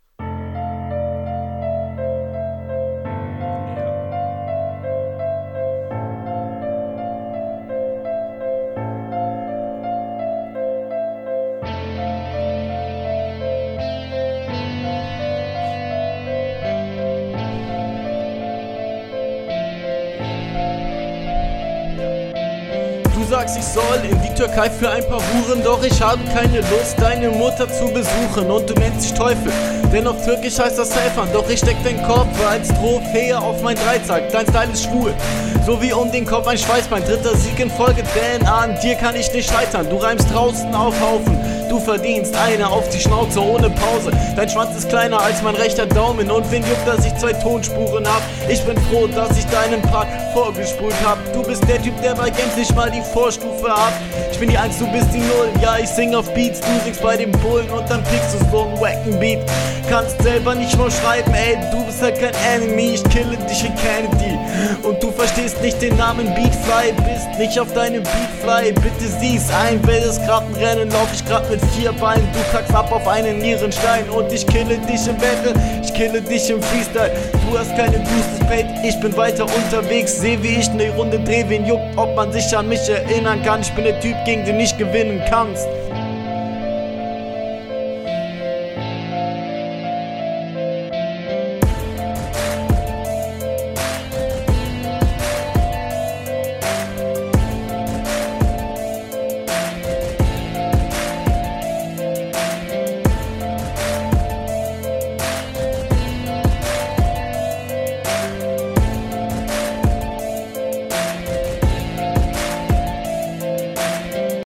Du bist deinem Gegner überlegen, du hast im Vergleich zu deinem Gegner echte Flowansätze.